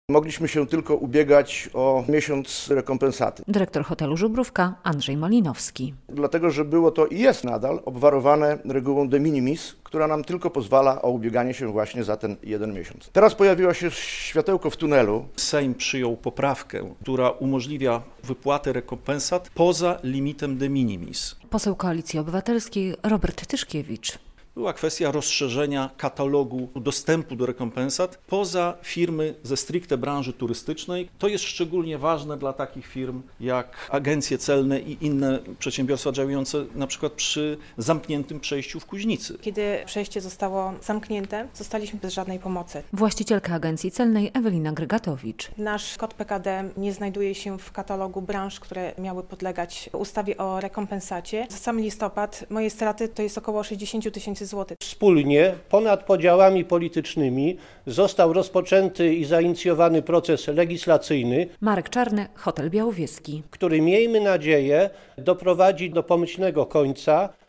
Problemy przedsiębiorców z terenów przygranicznych - relacja